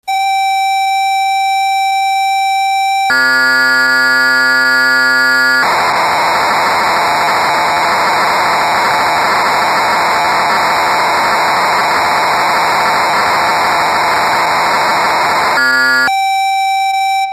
dw6000_factory.mp3